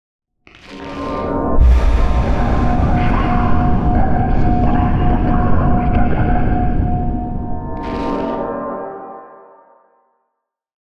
divination-magic-sign-rune-complete.ogg